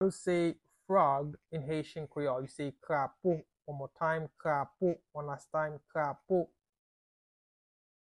Listen to and watch “Krapo” audio pronunciation in Haitian Creole by a native Haitian  in the video below:
How-to-say-Frog-in-Haitian-Creole-Krapo-pronunciation-by-a-Haitian-teacher.mp3